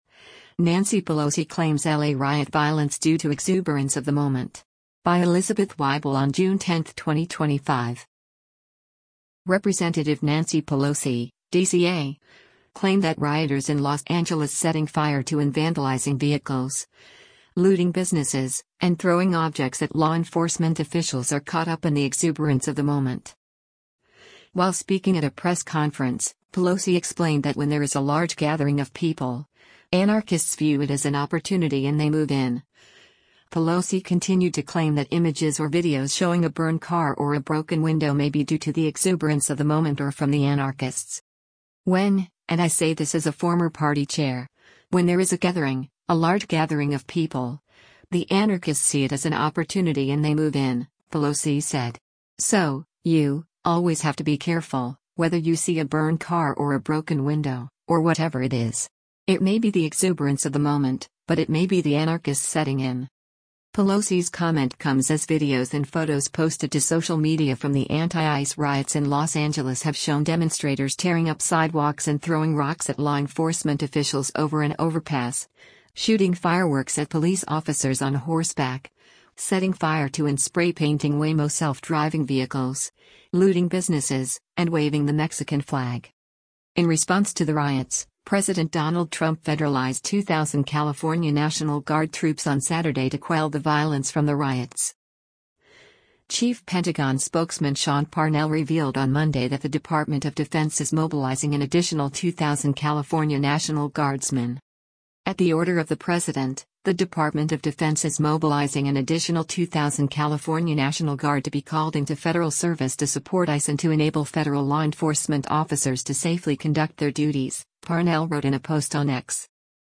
While speaking at a press conference, Pelosi explained that “when there is a large gathering” of people, anarchists view it “as an opportunity and they move in.”